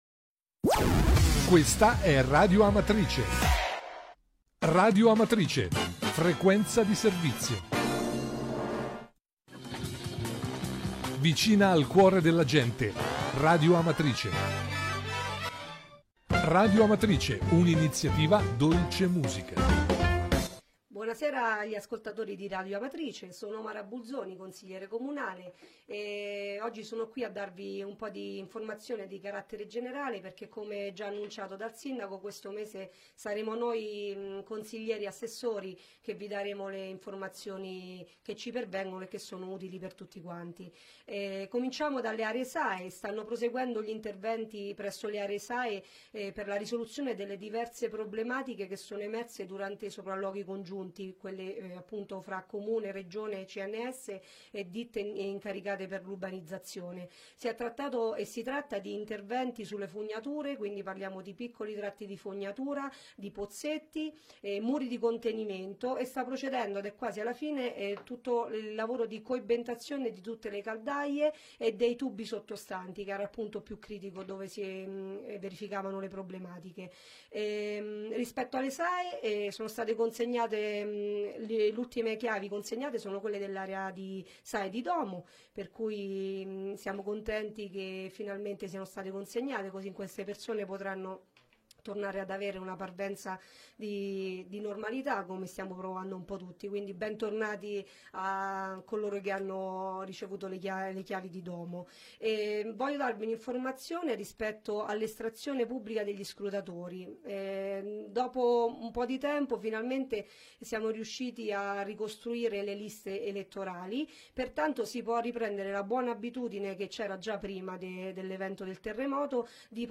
Di seguito il messaggio audio del Consigliere comunale Mara Bulzoni del 6 febbraio 2018.